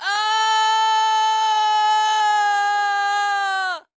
scream4.wav